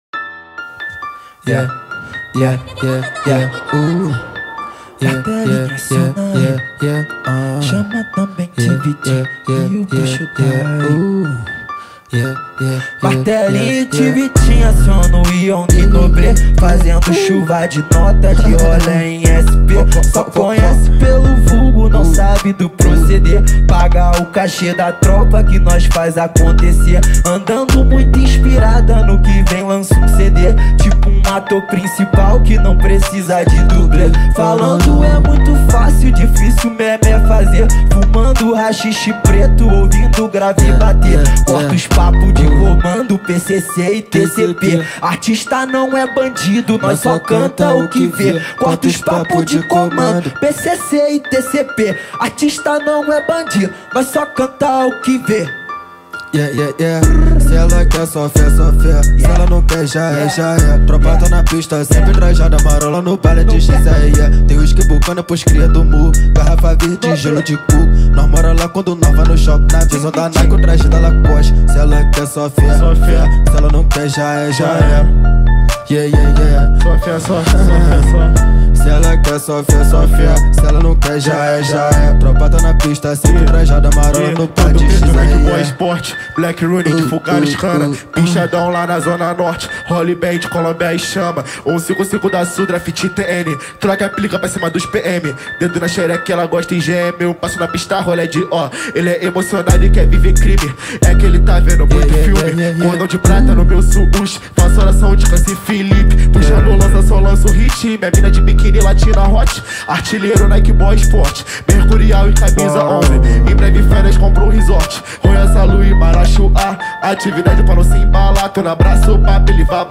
2024-07-21 12:16:20 Gênero: Funk Views